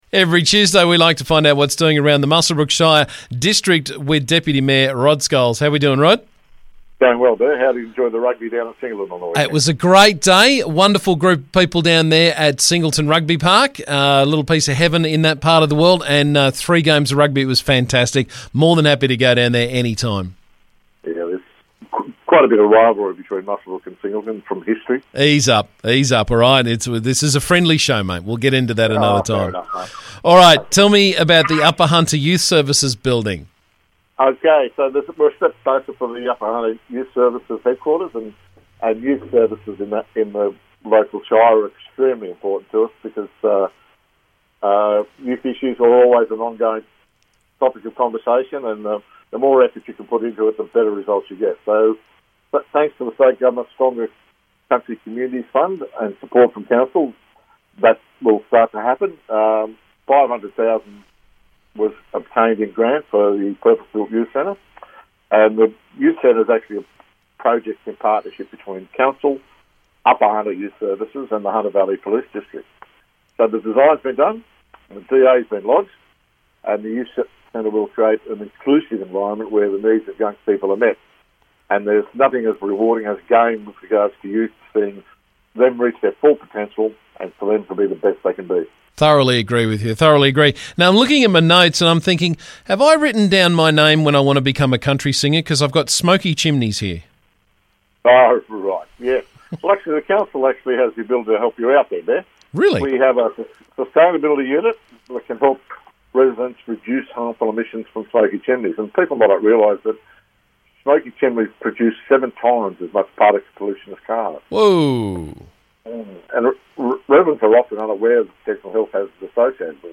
Muswellbrook Shire Council Deputy Mayor Rod Scholes joined me to talk about the latest from around the district.